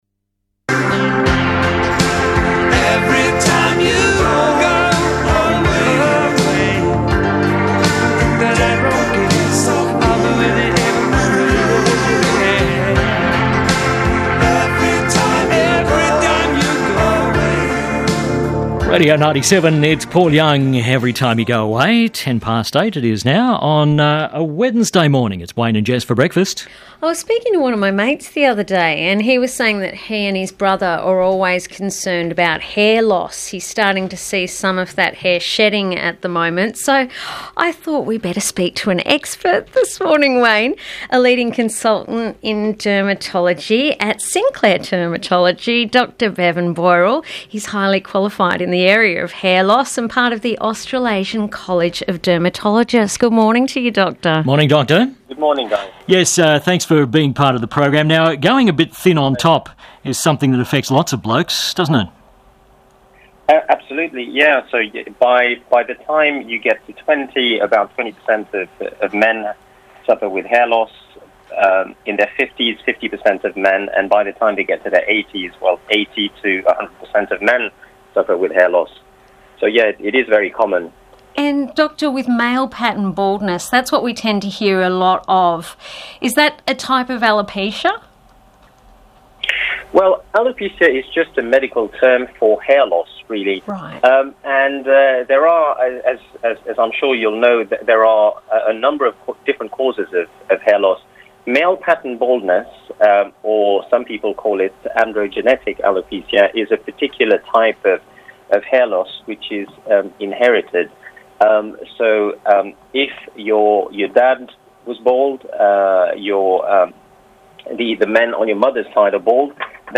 Hair loss interview